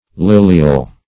Search Result for " lilial" : The Collaborative International Dictionary of English v.0.48: Lilial \Lil"i*al\ (l[i^]l"[i^]*al), a. (Bot.)